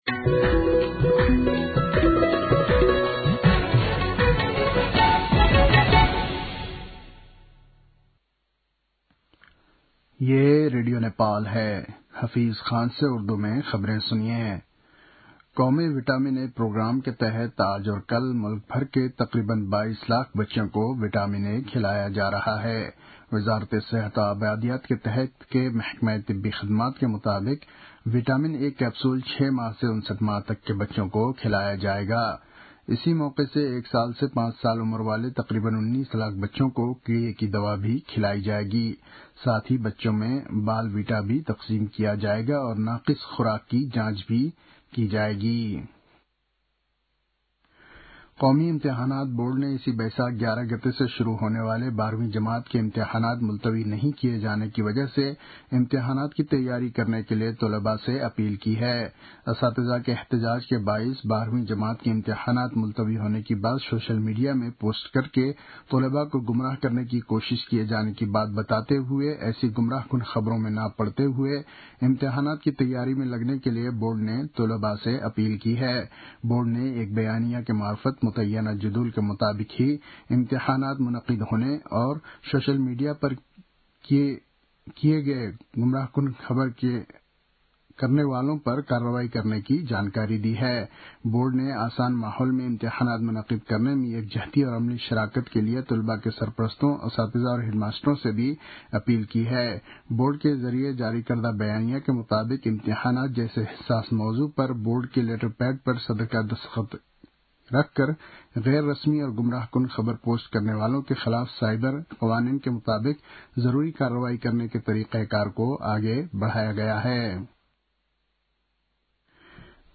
उर्दु भाषामा समाचार : ६ वैशाख , २०८२